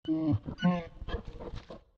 Minecraft Version Minecraft Version latest Latest Release | Latest Snapshot latest / assets / minecraft / sounds / mob / polarbear_baby / idle3.ogg Compare With Compare With Latest Release | Latest Snapshot